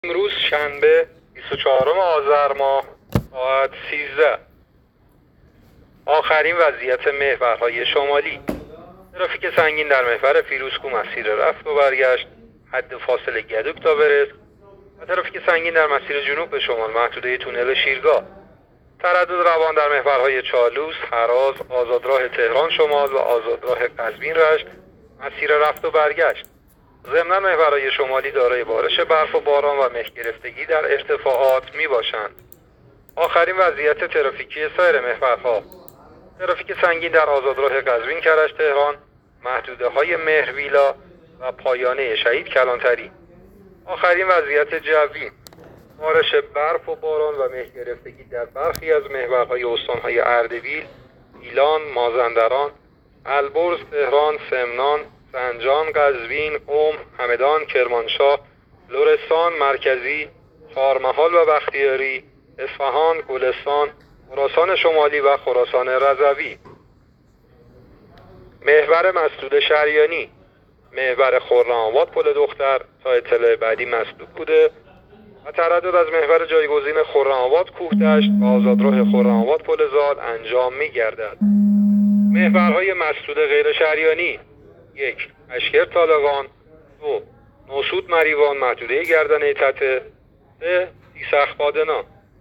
گزارش رادیو اینترنتی از آخرین وضعیت ترافیکی جاده‌ها تا ساعت ۱۳ بیست‌وچهارم آذر؛